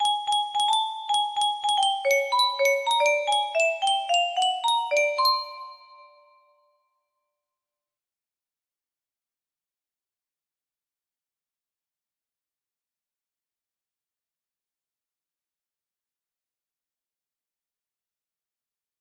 Unknown Artist - test music box melody